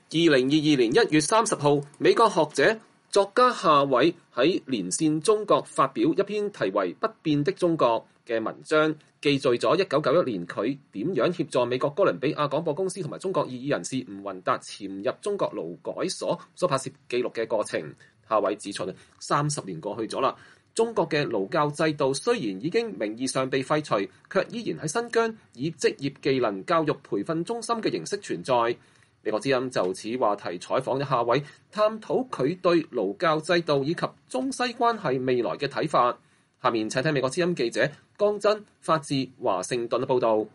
不變的中國？”(2) – 美國之音專訪學者夏偉